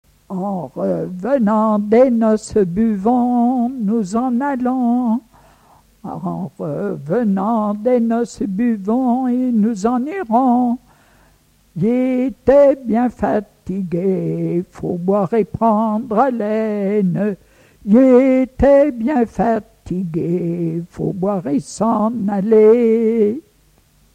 gestuel : à marcher
Pièce musicale inédite